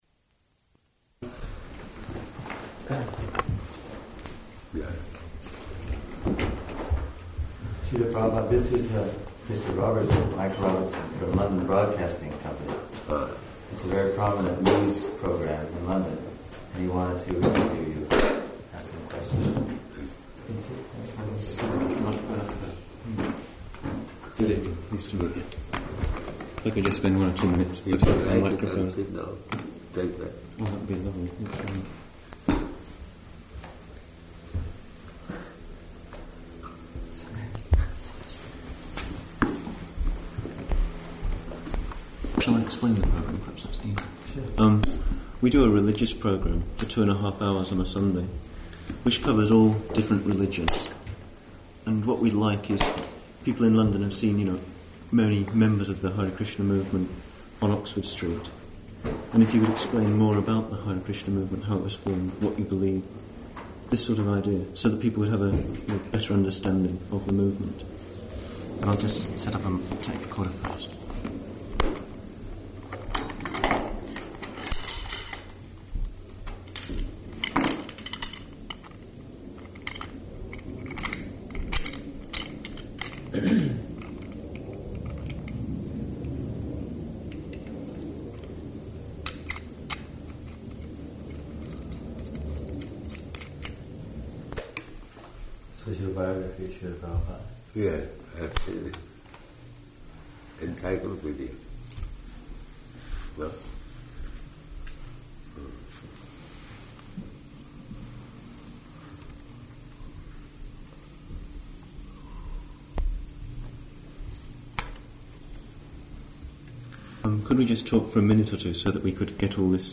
Type: Interview
Location: London